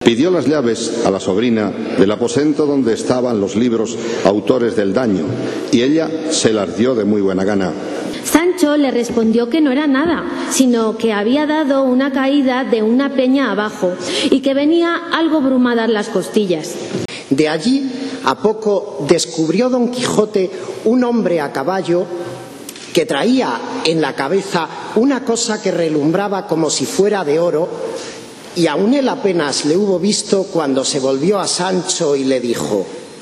La lectura arrancó en español con el inolvidable “En un lugar de La Mancha...”, a partir del cual se fueron intercalando los distintos lectores -todos ellos en braille- que nos trasladaron el texto en noruego, inglés, polaco, rumano, francés, italiano, portugués, ruso, japonés, esperanto, árabe, chino, alemán e incluso en asturiano, entre otros.